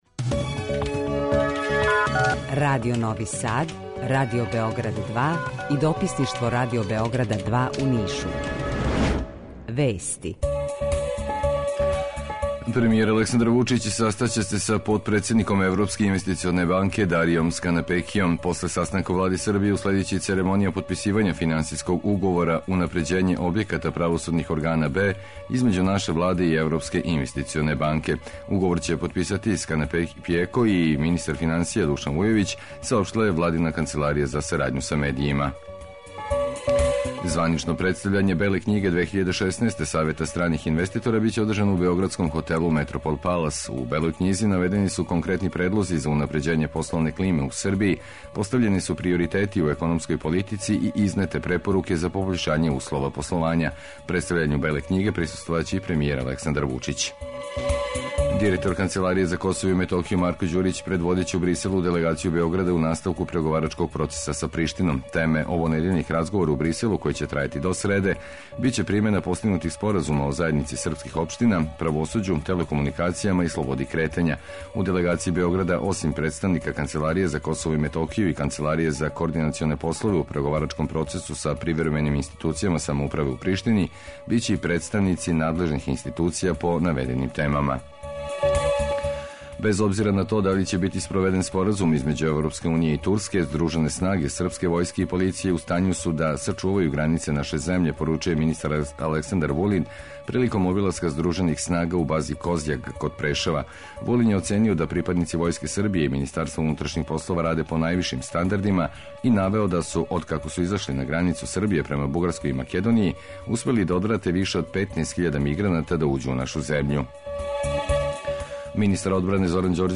Jутарњи програм заједнички реализују Радио Београд 2, Радио Нови Сад и дописништво Радио Београда из Ниша.
У два сата ту је и добра музика, другачија у односу на остале радио-станице.